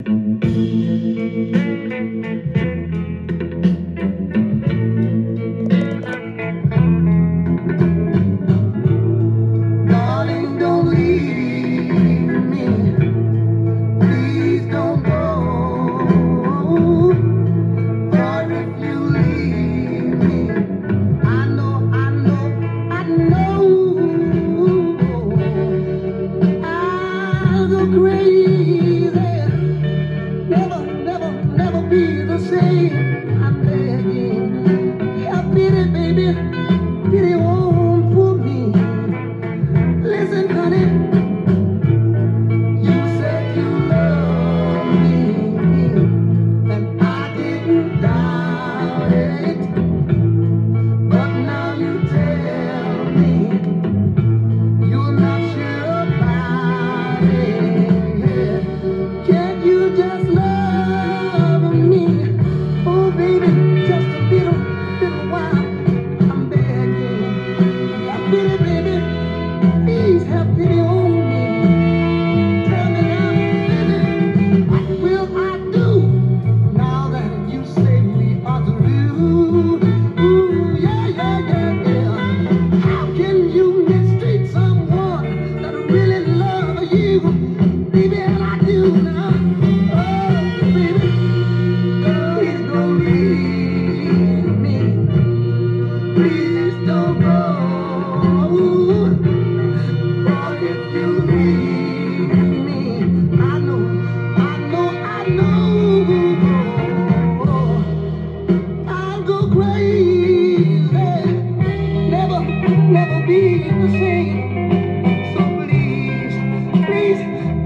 ジャンル：SOUL
店頭で録音した音源の為、多少の外部音や音質の悪さはございますが、サンプルとしてご視聴ください。